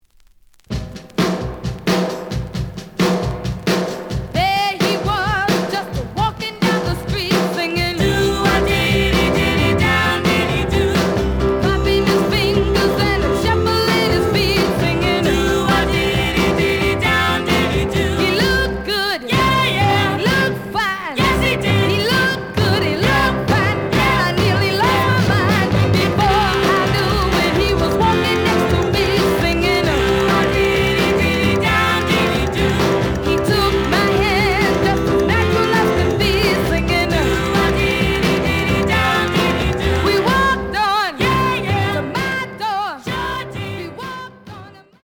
試聴は実際のレコードから録音しています。
The audio sample is recorded from the actual item.
●Genre: Rhythm And Blues / Rock 'n' Roll